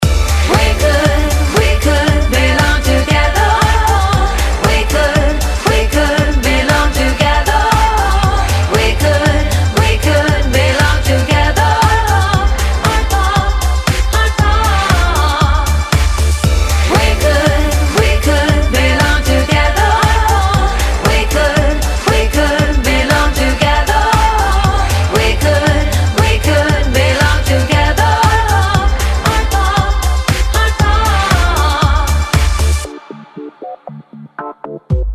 • Качество: 192, Stereo
громкие
женский вокал
Electropop